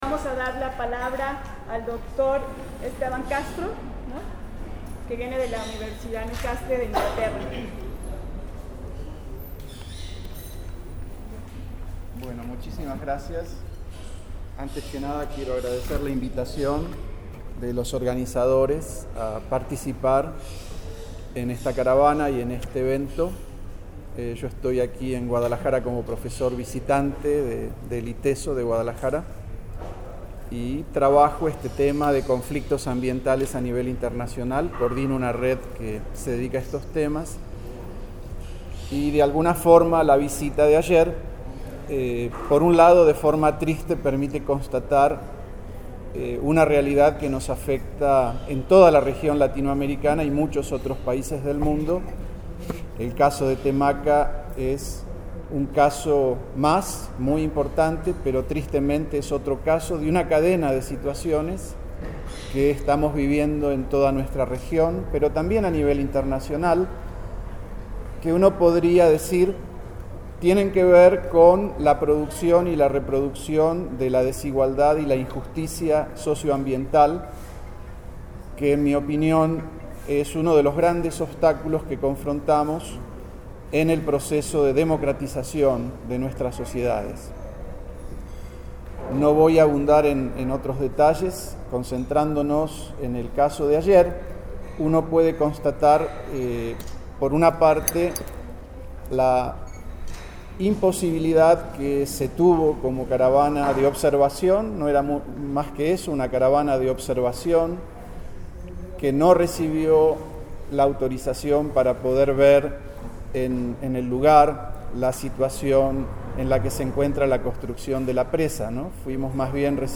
En conferencia de prensa se dio a conocer el resultado de la Auditoría popular a la presa el Zapotillo que afectará a las comunidades de Temacapulin, Palmarejo y Acasico en la que se señala la violación sistemática a los Derechos Humanos, las irregularidades en la construcción de la presa el Zapotillo, el posible desacato de la sentencia emitida por la Suprema Corte de Justicia de la Nación (SCJN), el mal manejo del agua en el estado de Jalisco y se exigió al presidente Enrique Peña Nieto cumplir los derechos de los pueblos originarios dejando de lado la política extractivista que tiene consecuencias irreparables al medio ambiente.